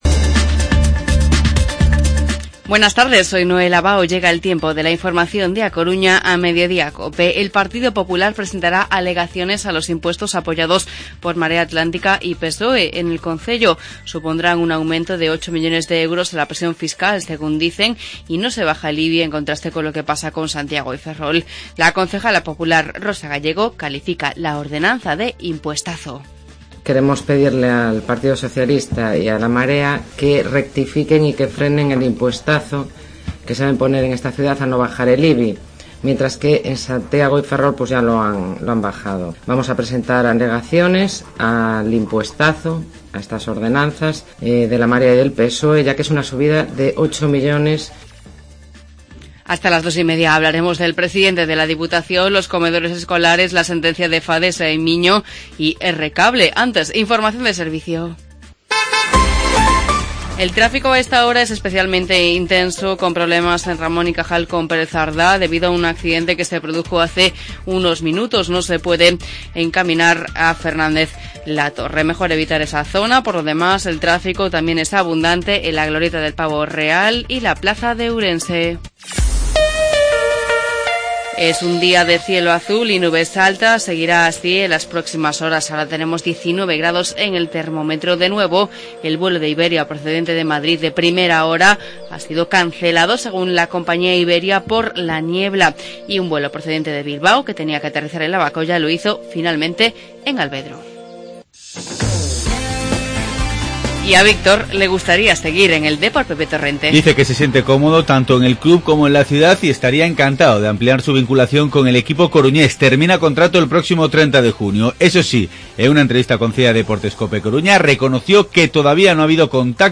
Informativo Mediodía COPE Coruña jueves, 12 de noviembre